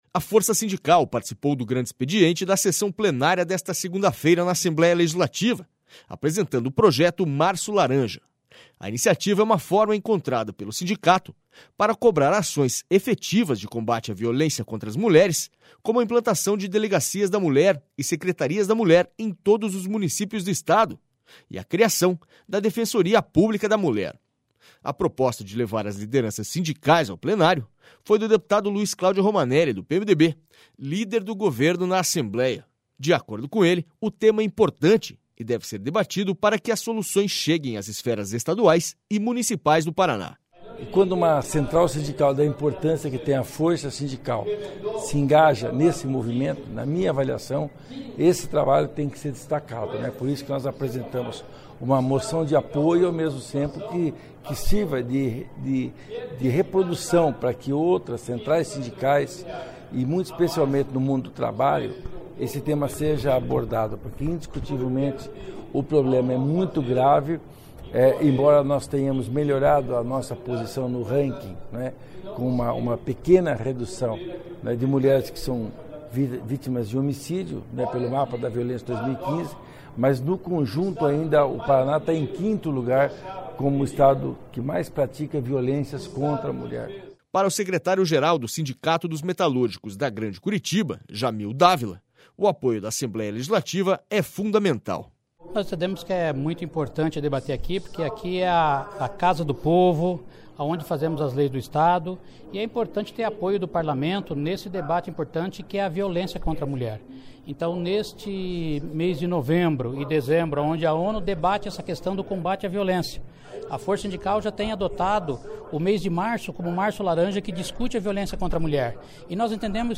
SONORA LUIZ CLÁUDIO ROMANELLI